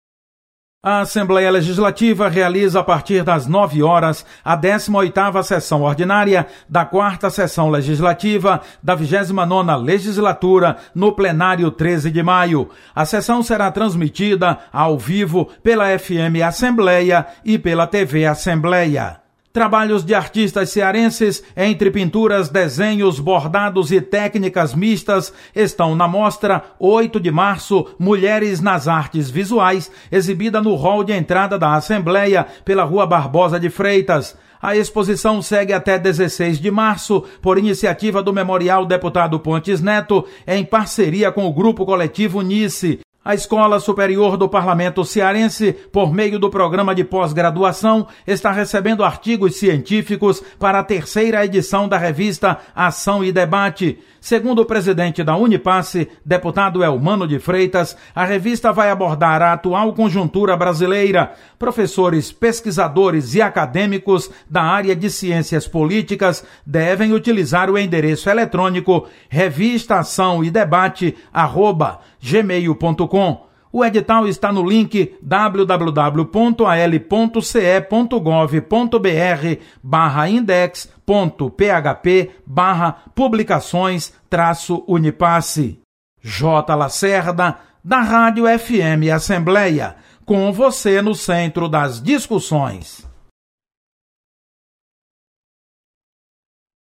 Acompanhe as atividades de hoje da Assembleia Legislativa com o repórter